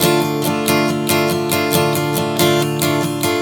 Strum 140 Dm 03.wav